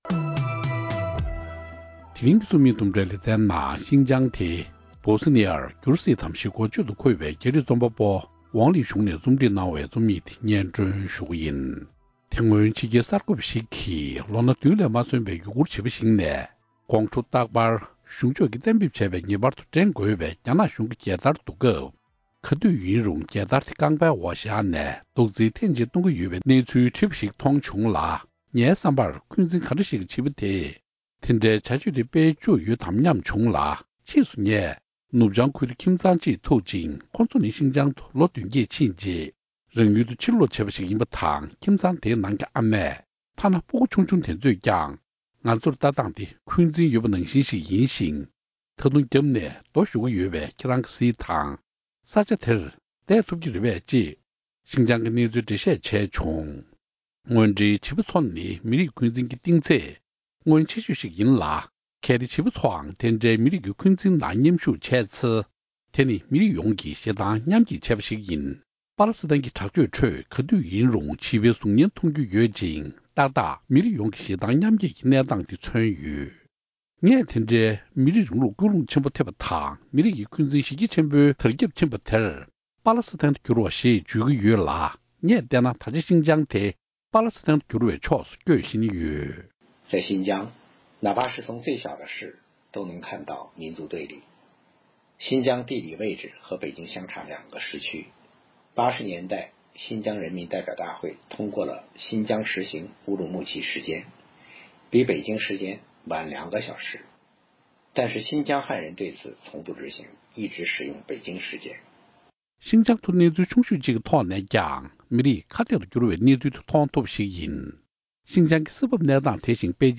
རྒྱ་རིགས་རྩོམ་པ་པོ་ཝང་ལིད་ཞུང་གིས་བྲིས་པའི་ཤིན་ཅང་འདི་བཞིན་སྦོའོ་སི་ཎི་ཡར་འགྱུར་སྲིད་དམ་ཞེས་འགོ་བརྗོད་དུ་འཁོད་པའི་རྩོམ་ཡིག་འདི་ཕབ་བསྒྱུར་སྙན་སྒྲོན་པ་ཞིག་གསན་རོགས་གནང་།